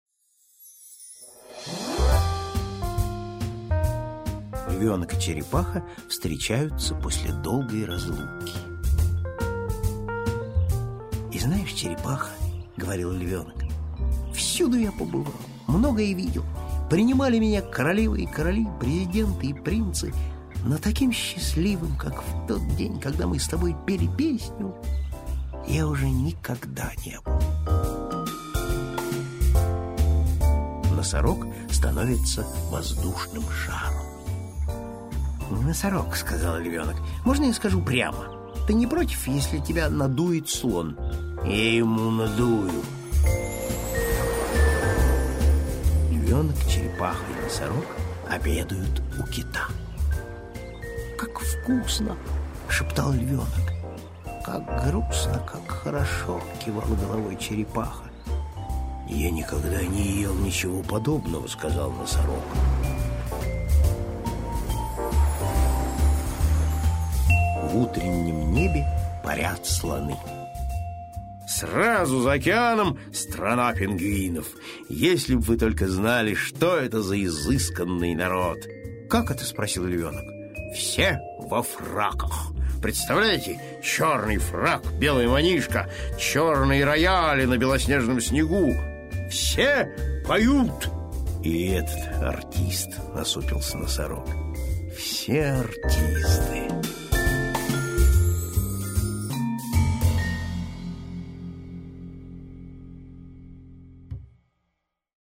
Аудиокнига Как львёнок и черепаха отправились в путешествие | Библиотека аудиокниг